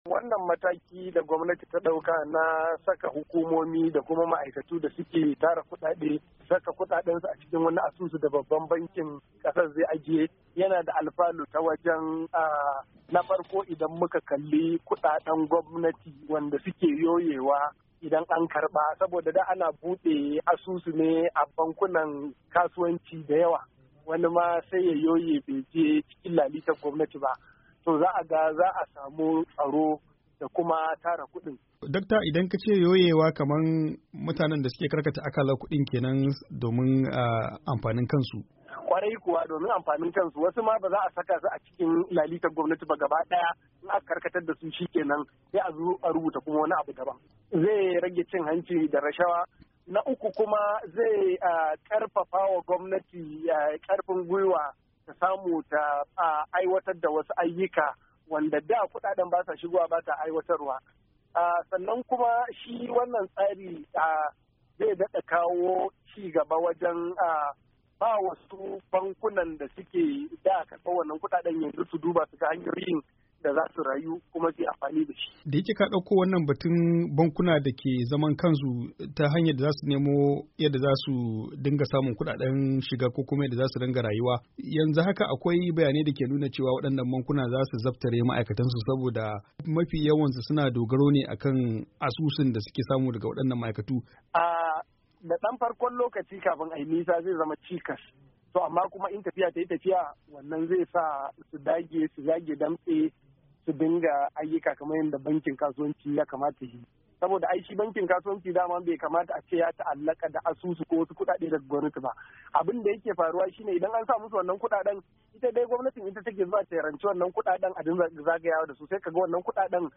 Cikakkiyar hirarsu